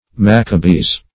Maccabees \Mac"ca*bees\, n. pl.